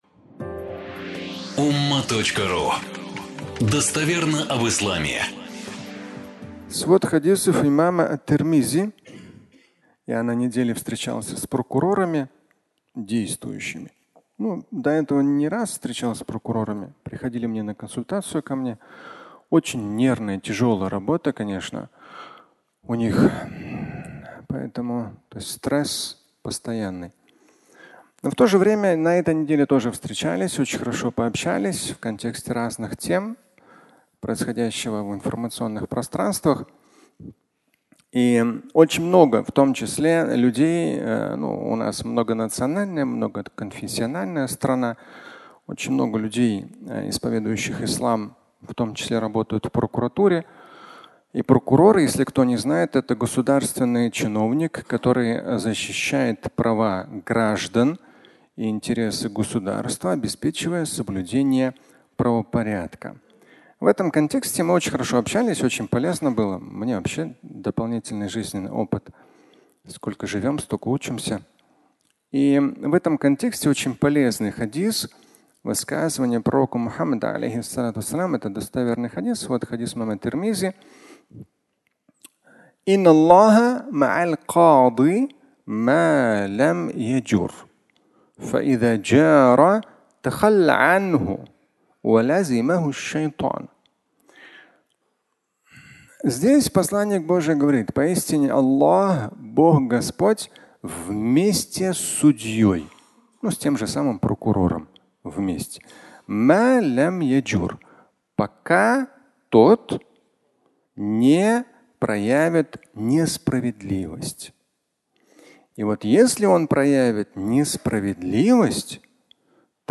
Встреча с прокурором (аудиолекция)